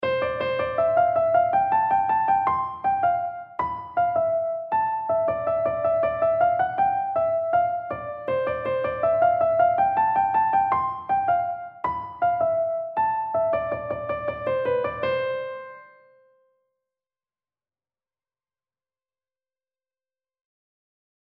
На ней было инструментальное эстрадное произведение под названием «Баркарола». Аудиозапись наиграна по памяти.
В приложенной записи возможны неточности исполнения.